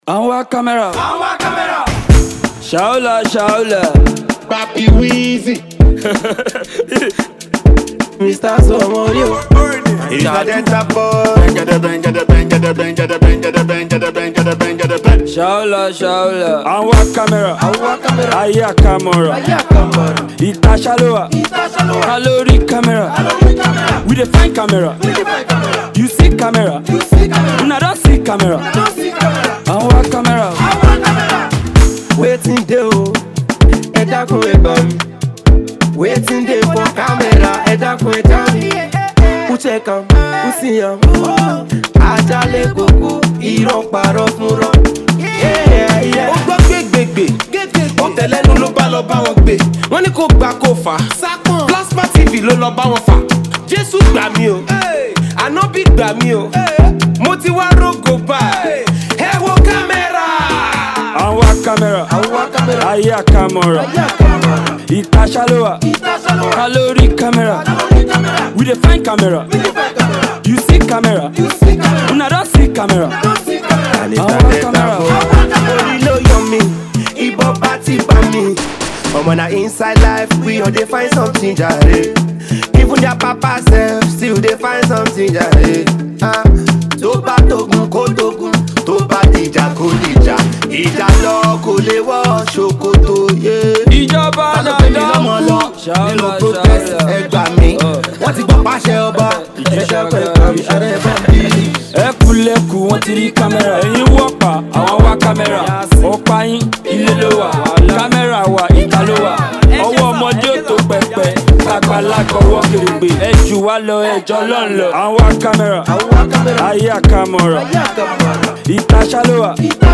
Nigerian street-hop singer